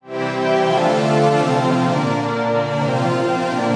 哨子
描述：一个同学的录音简短地吹口哨。
标签： 喜悦 快乐 口哨
声道立体声